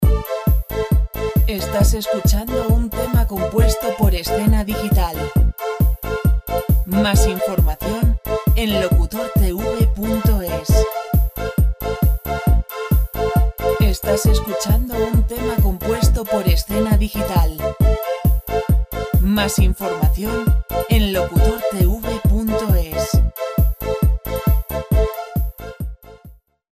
Royalty free dance music